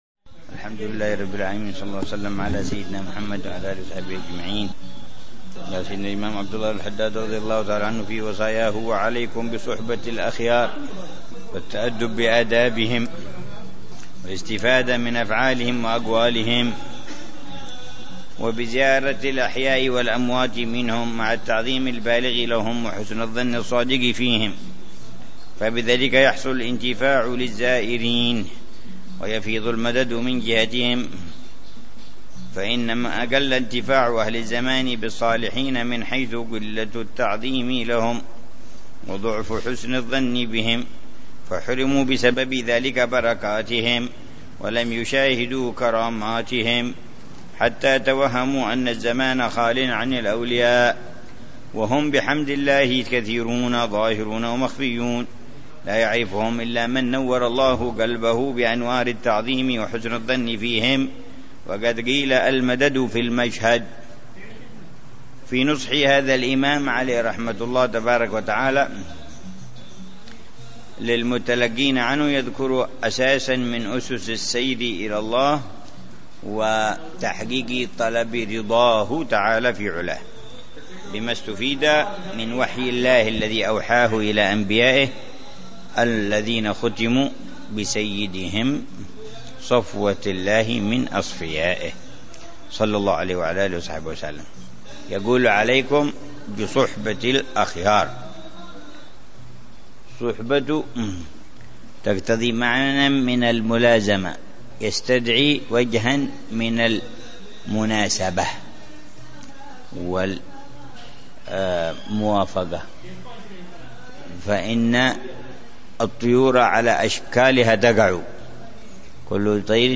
درس أسبوعي يلقيه الحبيب عمر بن حفيظ في كتاب الوصايا النافعة للإمام عبد الله بن علوي الحداد يتحدث عن مسائل مهمة في تزكية النفس وإصلاح القلب وطه